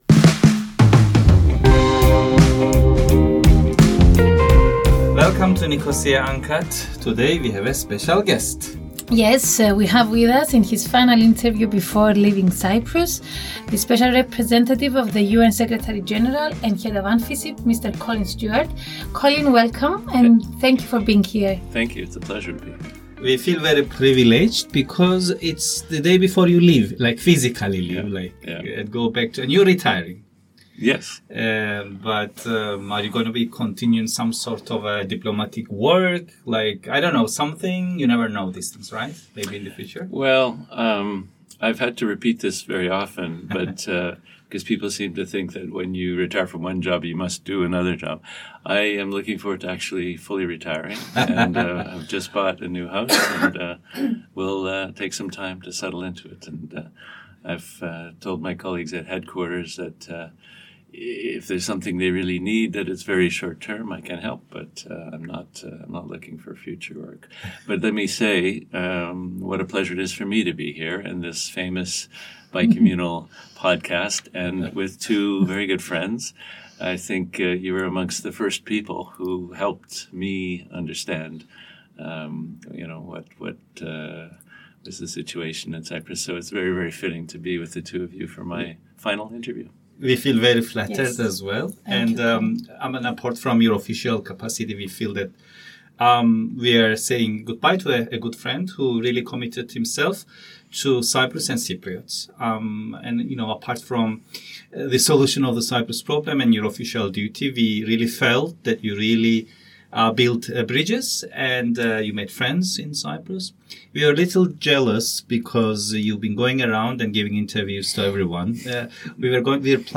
Nicosia Uncut – Episode 62: UNSG Special Representative Colin Stewart answered your questions (7/8/2025) - Island Talks